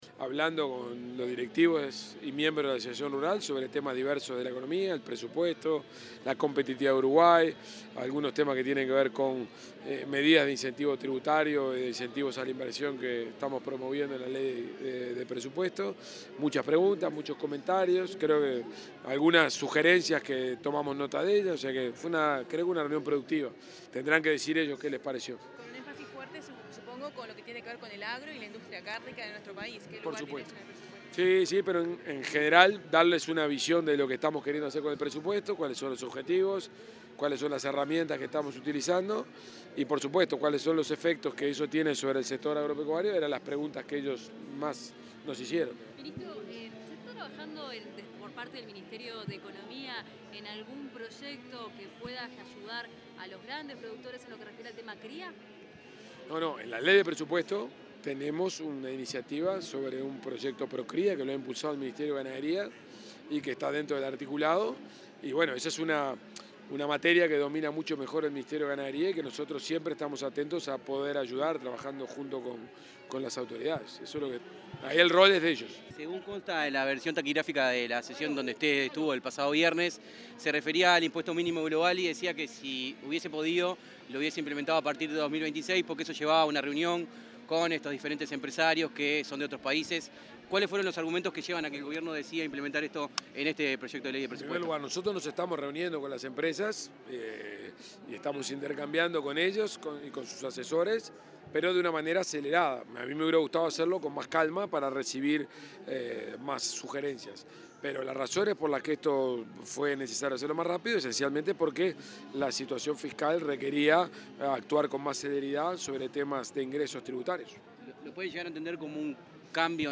Declaraciones del ministro de Economía, Gabriel Oddone, en la Expo Prado 2025